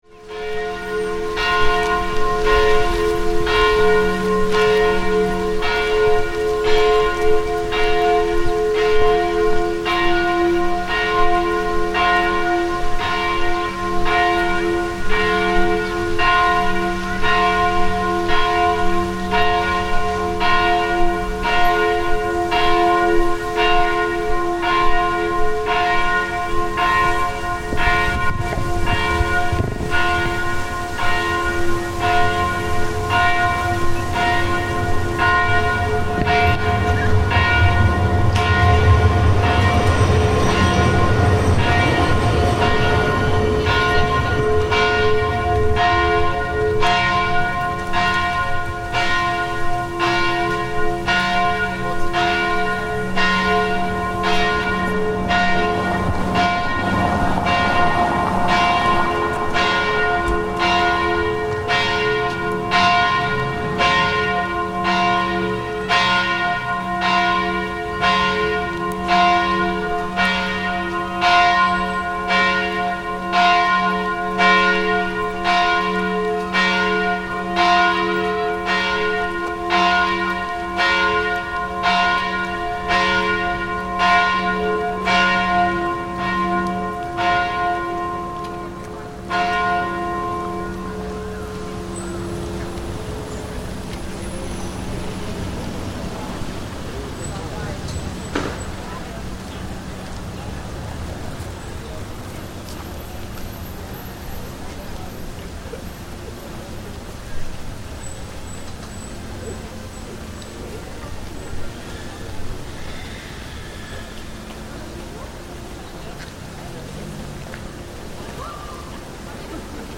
Walking through the Grønland district in Oslo, a pealing of bells starts up from a nearby church to mark the beginning of a wedding starting inside. The bells end, and we walk through the nearby Bierkelunden park, listening to passers-by talking, and passing close by a fountain.